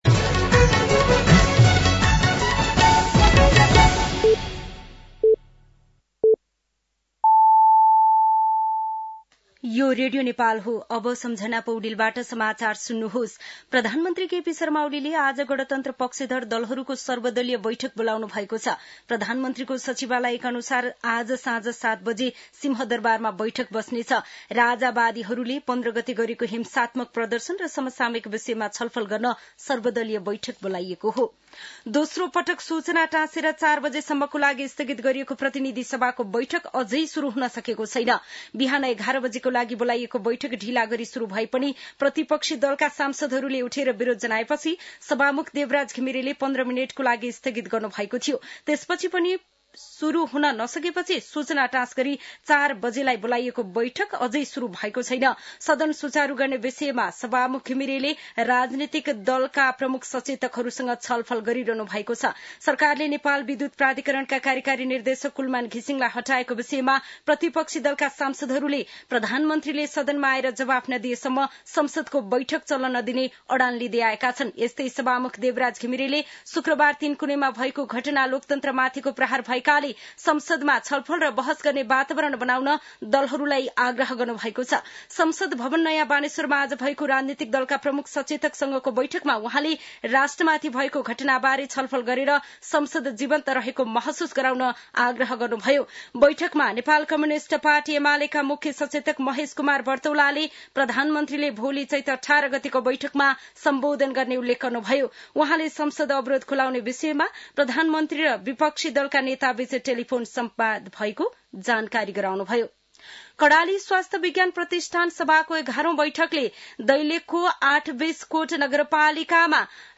साँझ ५ बजेको नेपाली समाचार : १७ चैत , २०८१
5-pm-news-9.mp3